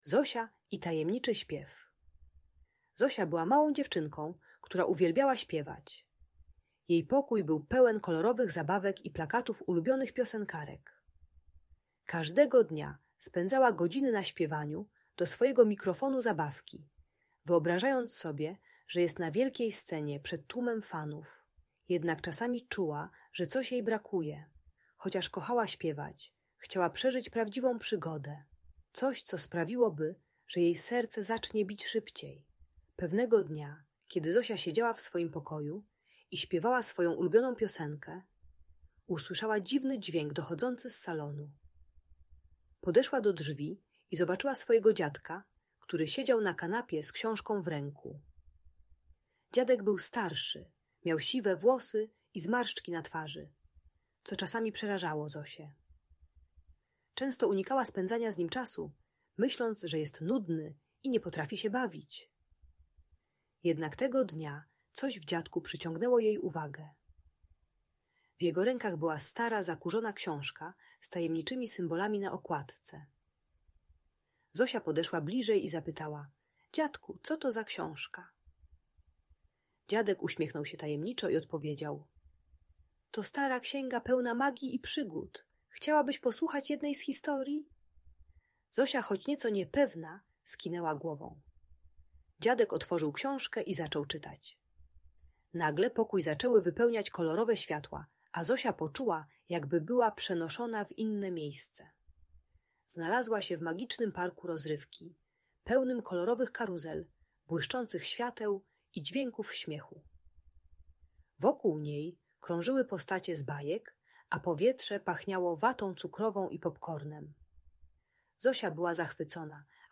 Zosia i Tajemniczy Śpiew - magiczna opowieść - Audiobajka